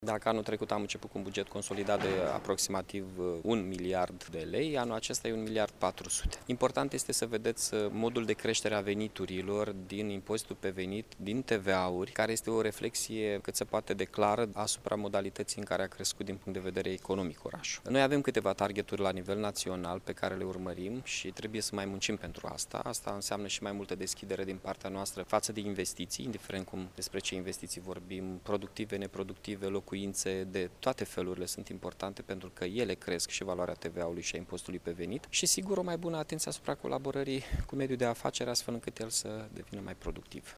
După 4 ore de dezbateri, bugetul municipiului Iaşi a fost aprobat, astăzi, în şedinţa Consiliului Local.
Primarul Iaşului, Mihai Chirica, a declarat că este un buget echilibrat, cu o importantă infuzie de fonduri europene datorită proiectelor depuse.